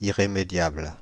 Ääntäminen
Ääntäminen Paris: IPA: [i.ʁe.me.djabl] France (Île-de-France): IPA: /i.ʁe.me.djabl/ Haettu sana löytyi näillä lähdekielillä: ranska Käännös 1. onherstelbaar Suku: f .